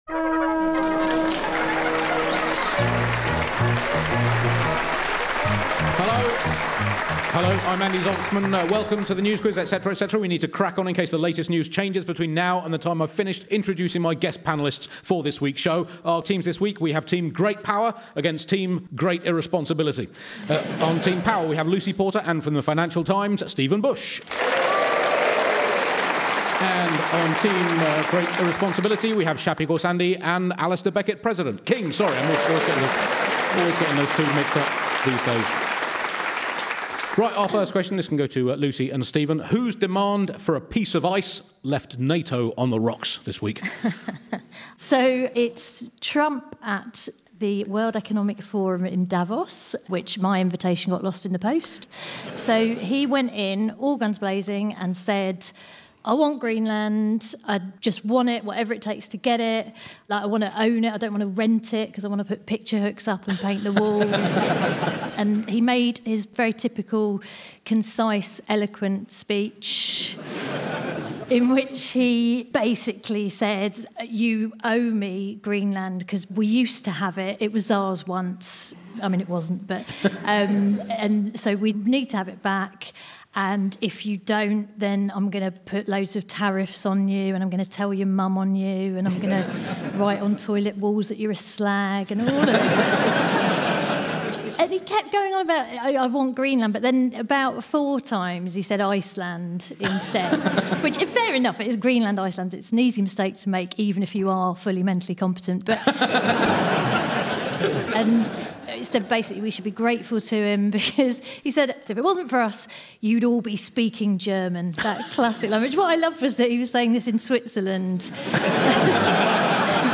Joining Andy to help make sense of all the madness is Lucy Porter, Alasdair Beckett-King, Stephen Bush and Shappi Khorsandi.